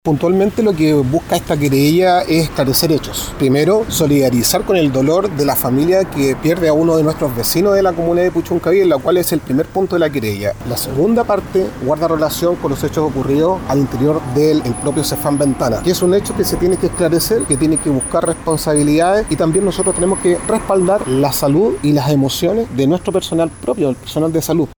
El alcalde de Puchuncaví, Marcos Morales, señaló que estas acciones judiciales buscan que se realice la denuncia correspondiente, se investiguen los hechos y se esclarezca lo ocurrido.